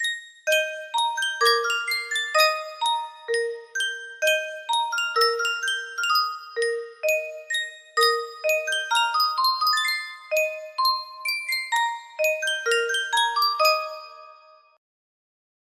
Sankyo Music Box - I'll Take You Home Again Kathleen MGM music box melody
Full range 60